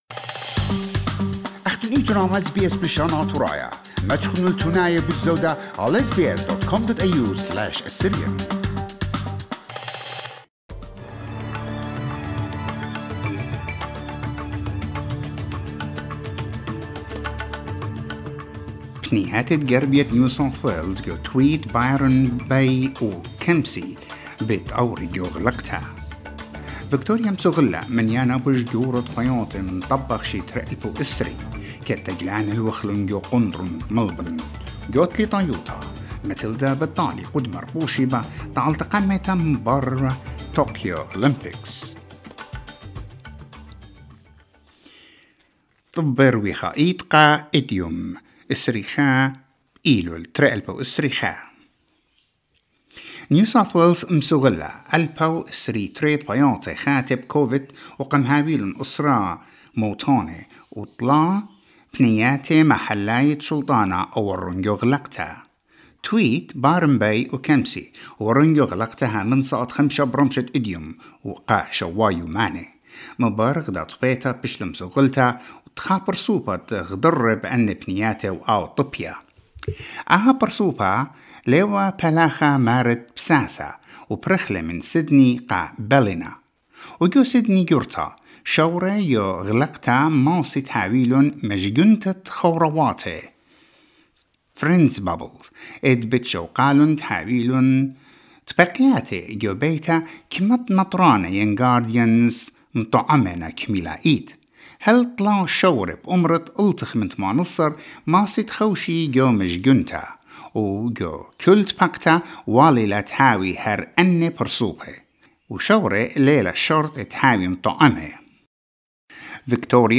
NEWS BULLETIN FOR TUESDAY 21 SEPTEMBER 2021